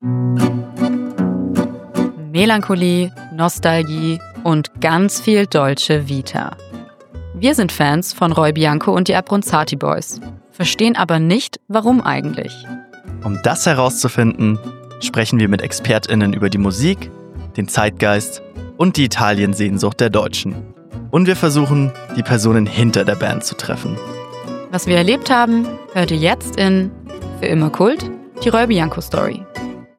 Trailer – Für immer Kult? Die Roy Bianco Story